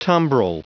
Prononciation du mot tumbrel en anglais (fichier audio)
Prononciation du mot : tumbrel